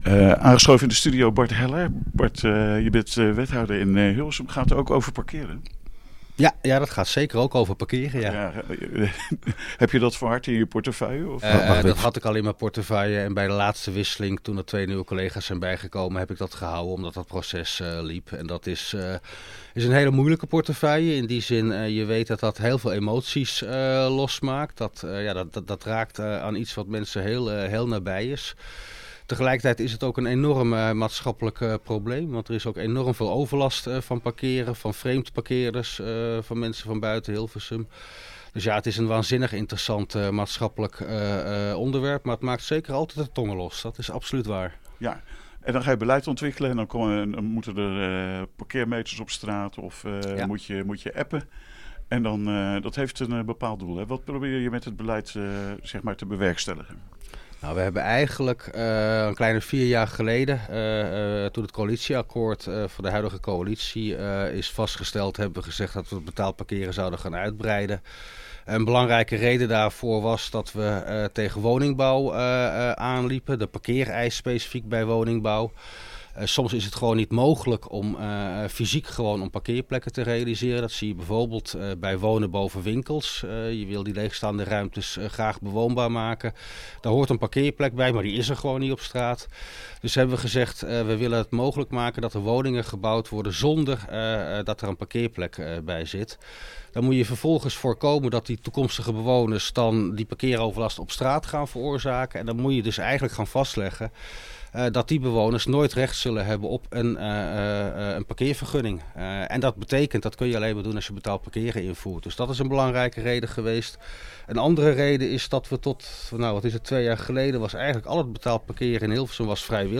Te gast in de studio wethouder Bart Heller om ons even bij te praten.